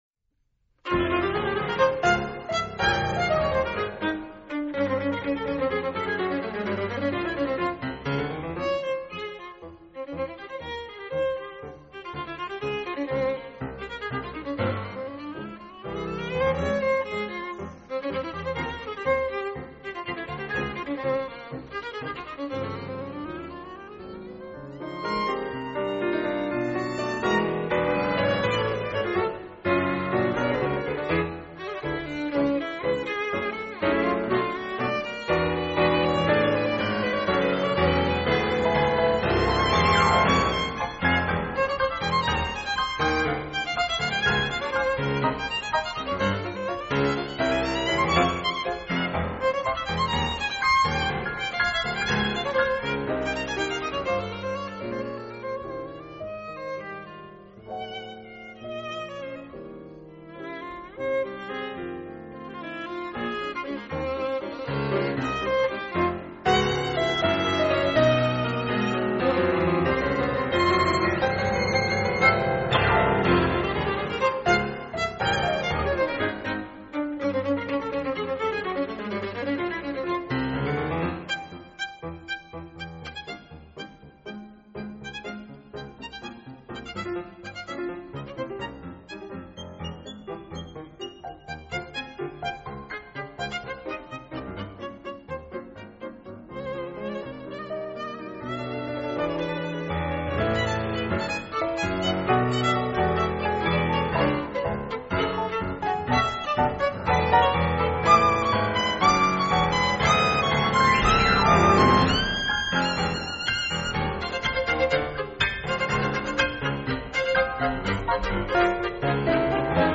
a piece for violin and piano